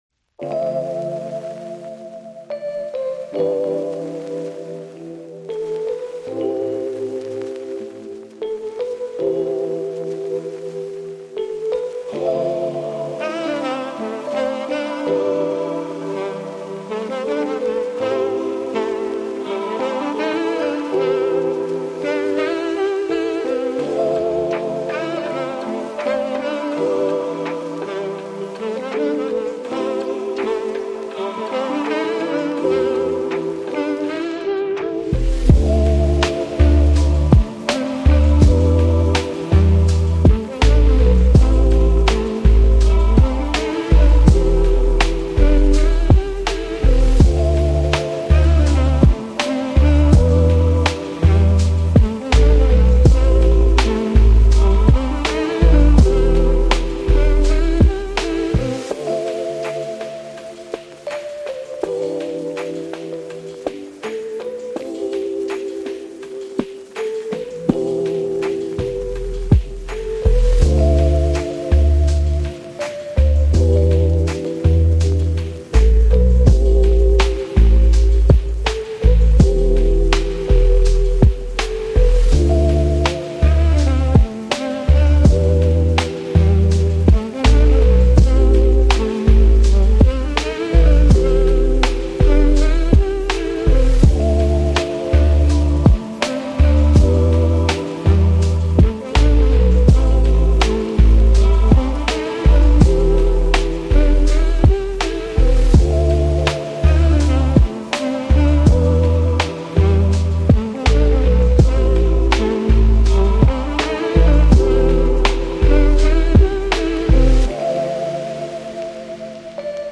Lofi Luxury